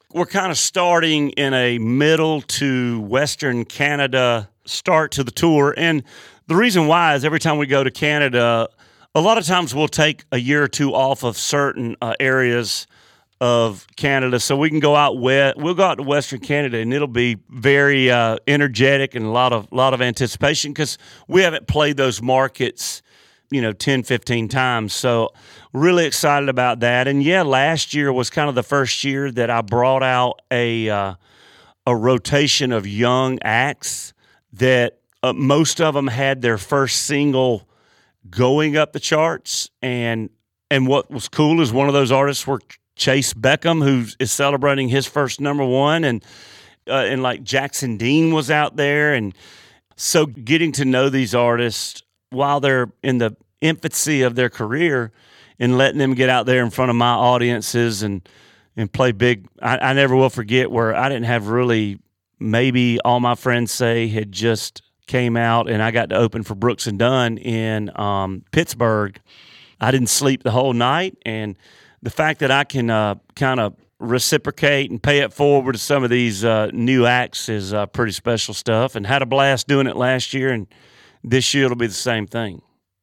Luke Bryan talks about his "Mind of a Country Boy Tour."